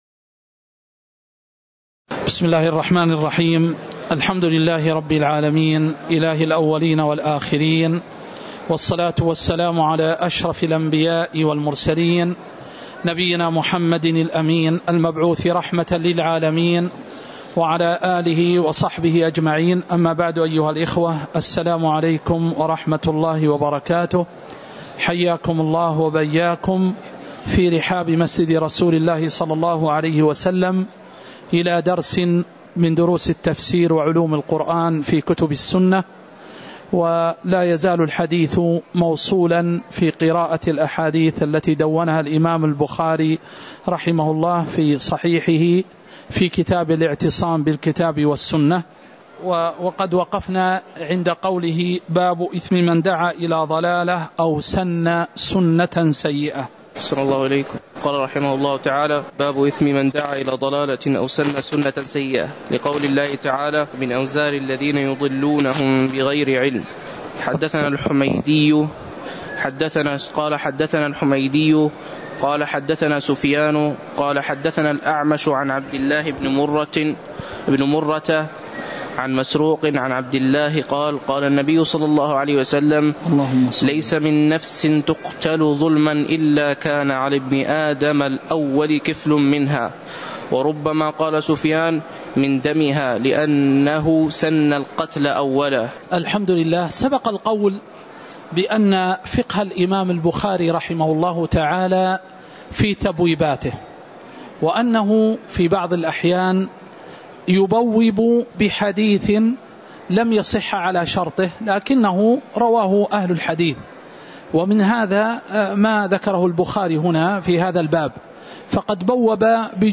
تاريخ النشر ٩ شعبان ١٤٤٠ هـ المكان: المسجد النبوي الشيخ